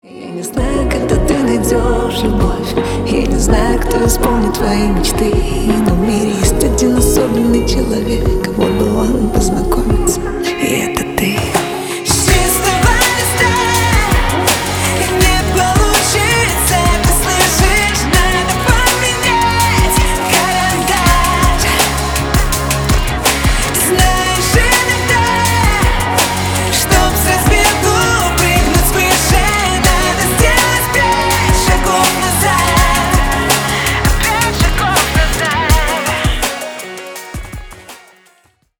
Поп Музыка # Рэп и Хип Хоп
спокойные